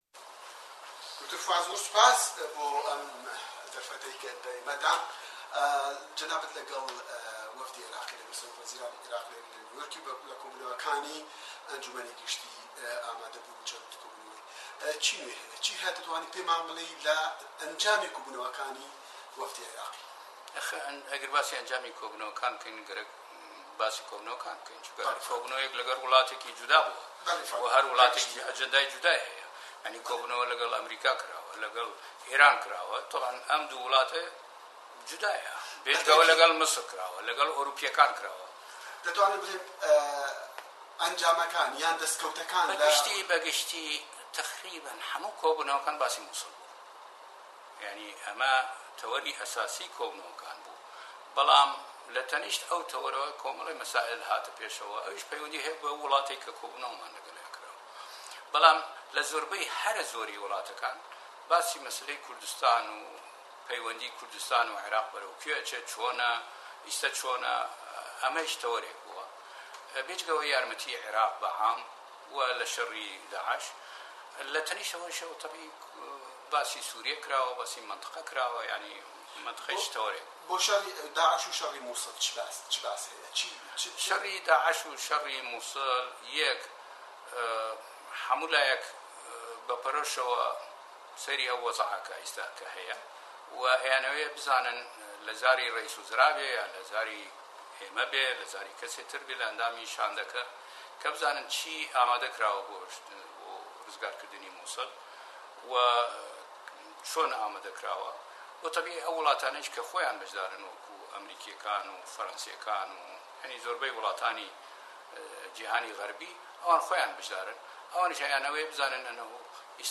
Hevpeyvîna Dr Fûad Husên-9-22-16